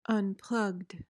PRONUNCIATION: (un-PLUHGD) MEANING: adjective: 1.